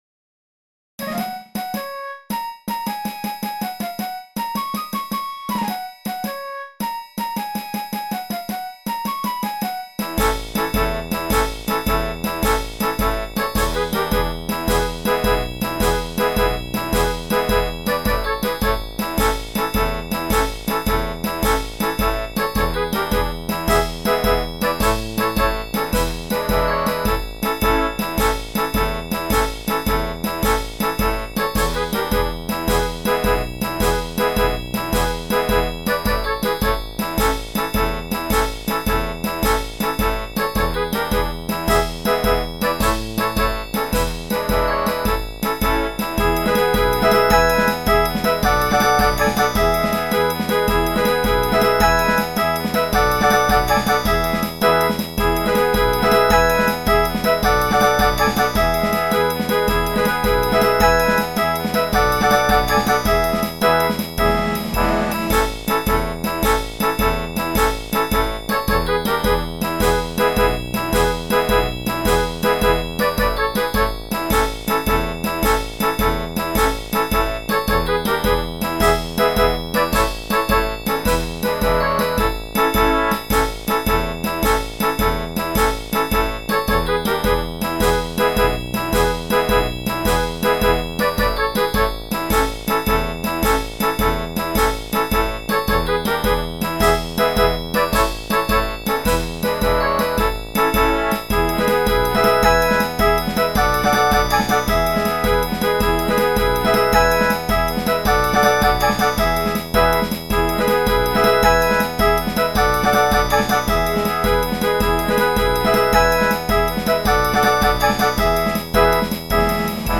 EMU Proteus 2 synthesizer
now including the trio section that is a march in itself
Music / Classical